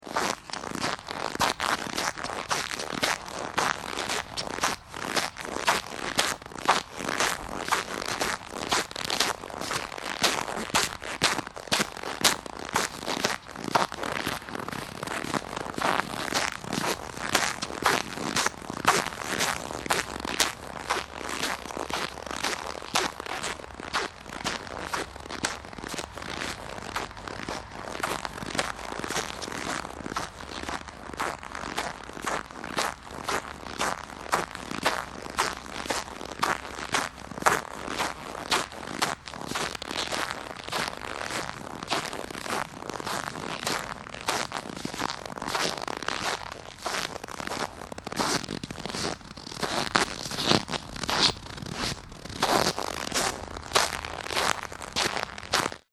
Звук - Хруст снега под ногами
Отличного качества, без посторонних шумов.
1313_sneg.mp3